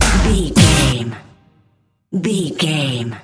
Aeolian/Minor
synthesiser
drum machine
90s
Eurodance